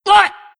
Worms speechbanks
Ow2.wav